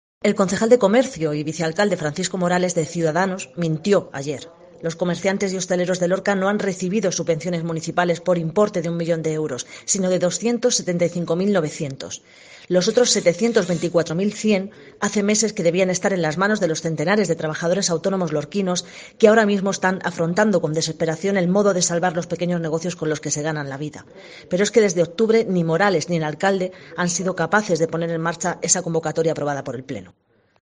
Gloria Martín, edil de IU Verdes en Lorca